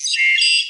computer3.ogg